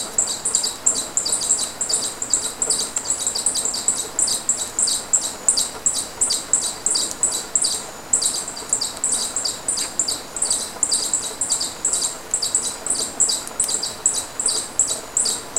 Picaflor Copetón (Stephanoxis loddigesii)
Nombre en inglés: Purple-crowned Plovercrest
Localidad o área protegida: Reserva de Biosfera Yabotí
Condición: Silvestre
Certeza: Vocalización Grabada
Pica-copeton-Mocona-D3-182.mp3